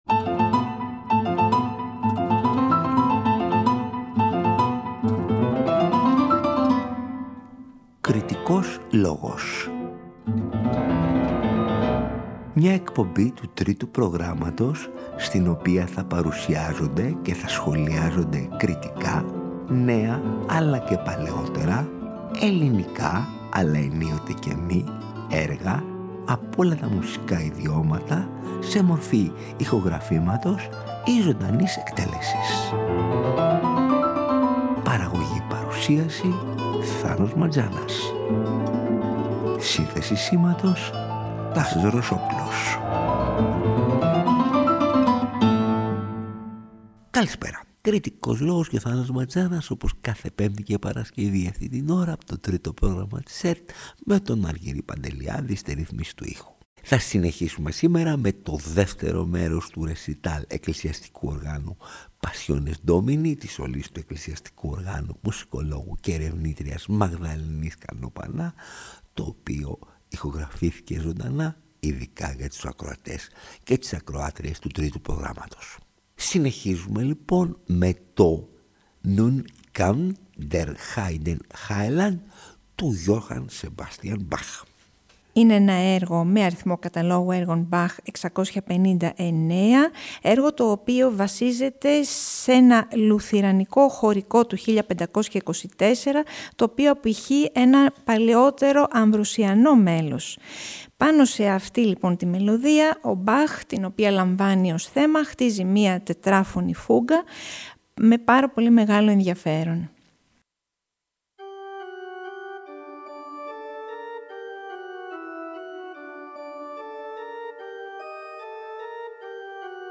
ρεσιτάλ εκκλησιαστικού οργάνου
Το ρεσιτάλ εκκλησιαστικού οργάνου ηχογραφήθηκε ζωντανά, χωρίς επιπλέον παρεμβάσεις στο στούντιο και αποκλειστικά για τους ακροατές και τις ακροάτριες του Τρίτου Προγράμματος.
μπαρόκ
Όλα με το μαγευτικό, ατμοσφαιρικό και υποβλητικό εύρος ηχοχρωμάτων του εκκλησιαστικού οργάνου που, ανεξάρτητα από την δυτική προέλευση του, προσδίδει μια